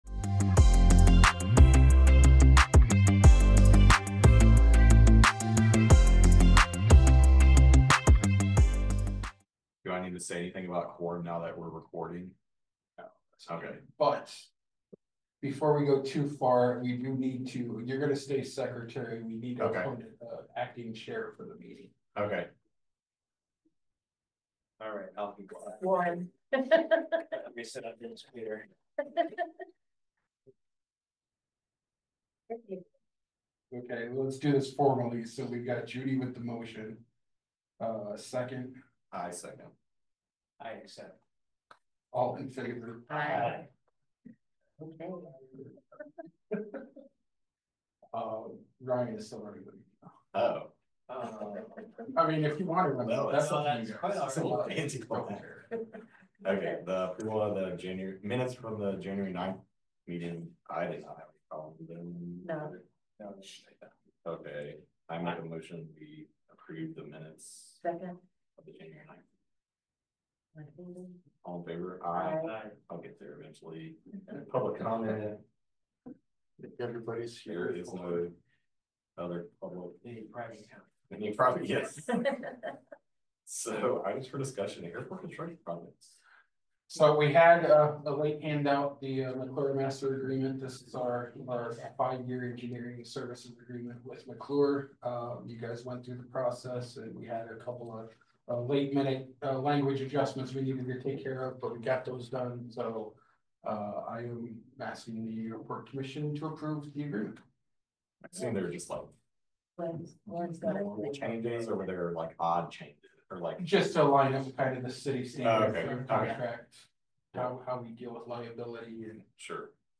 Regular monthly meeting of the City of Iowa City's Airport Commission.